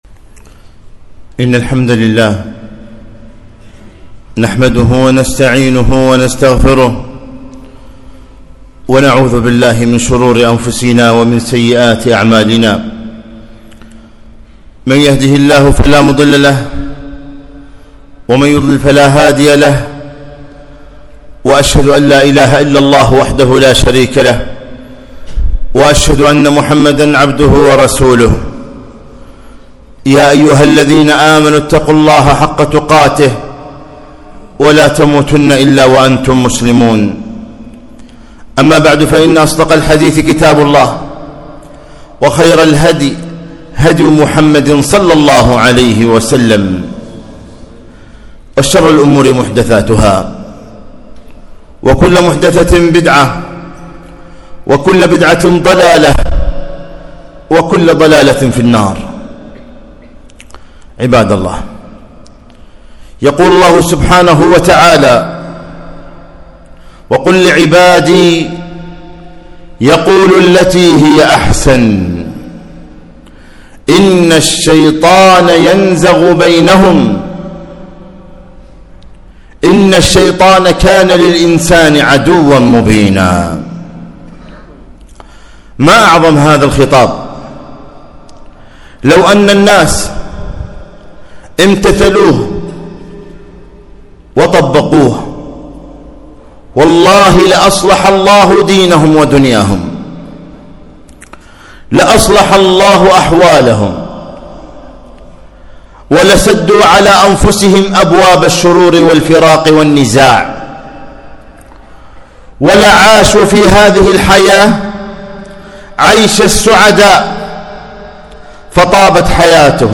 خطبة - ( قل لعبادي يقولوا التي هي أحسن )